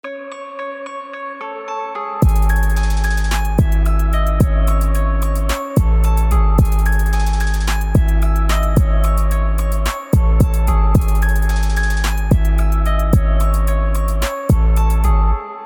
Elektronickou hudbu tvořím přes 4 roky.